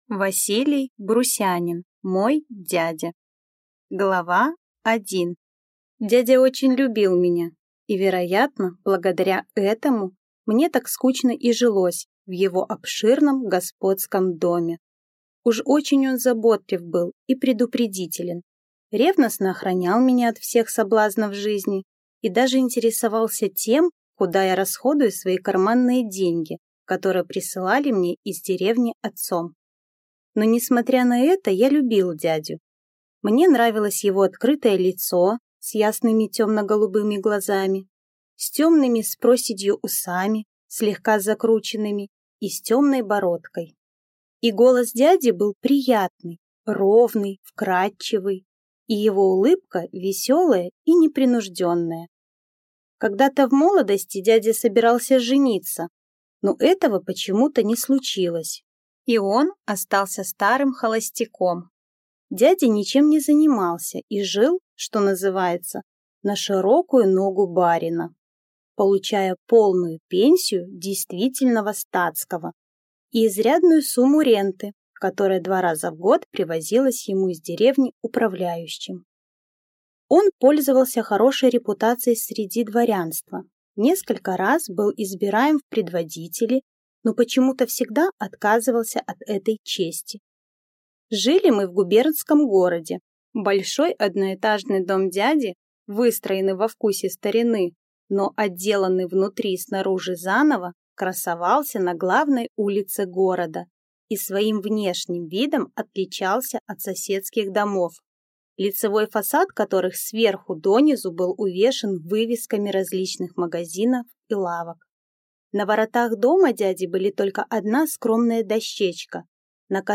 Аудиокнига Мой дядя | Библиотека аудиокниг